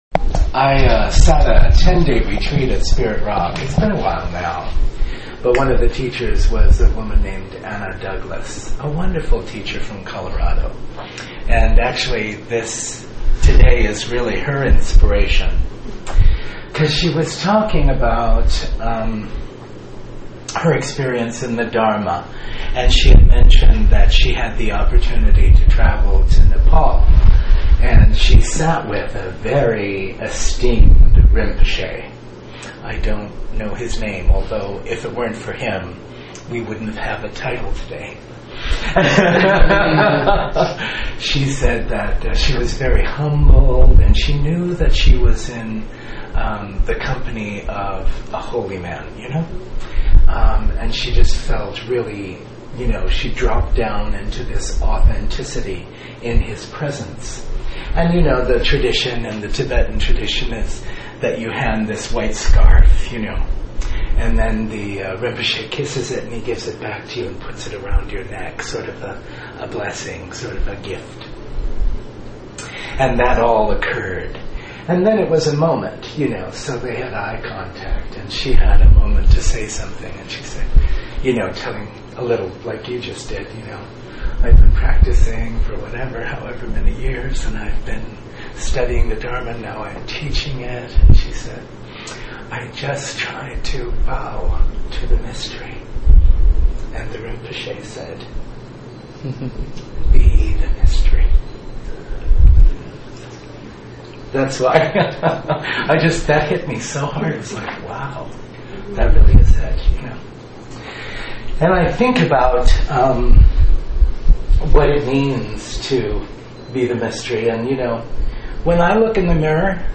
Dharma Talk and Exercise